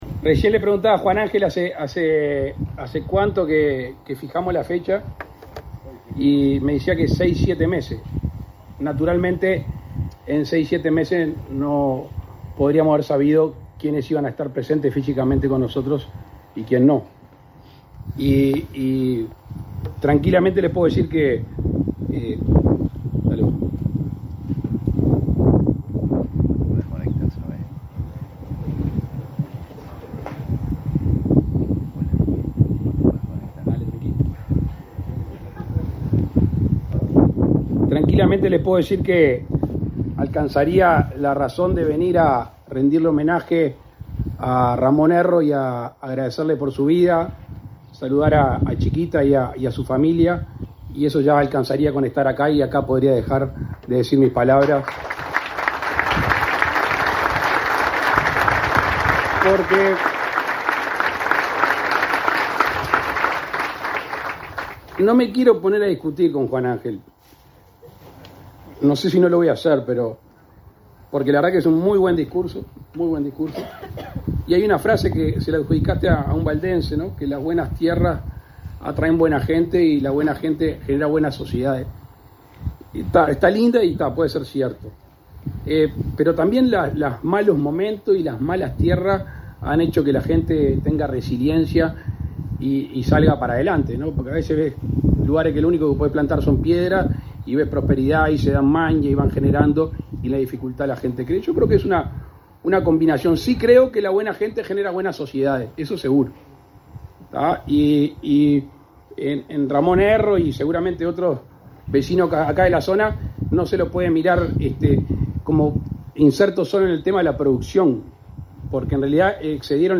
Palabras del presidente de la República, Luis Lacalle Pou
Palabras del presidente de la República, Luis Lacalle Pou 17/11/2023 Compartir Facebook X Copiar enlace WhatsApp LinkedIn Con la presencia del presidente de la República, Luis Lacalle Pou, se realizó, este 17 de noviembre, la inauguración de la cosecha de trigo, en la ciudad de Dolores, departamento de Soriano.